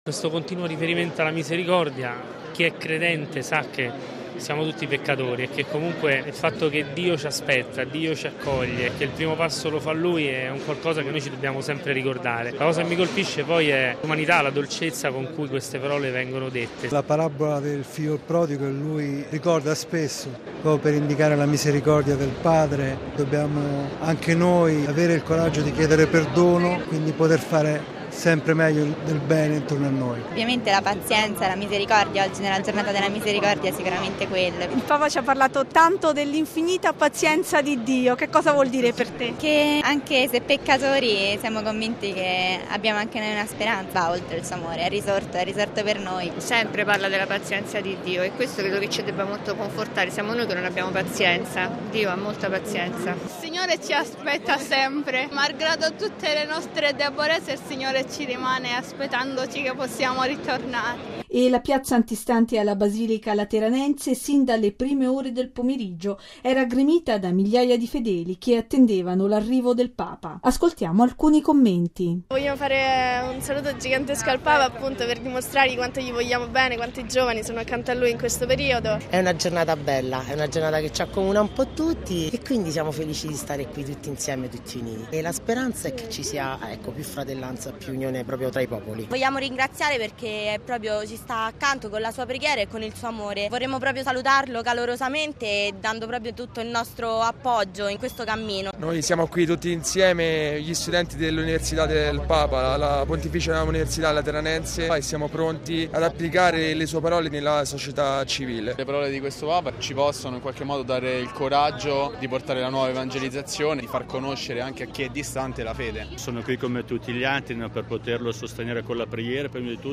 ◊   Numerosi i fedeli che hanno affollato la Basilica di San Giovanni in Laterano per ascoltare le parole di Papa Francesco. Ma cosa è rimasto nel loro cuore?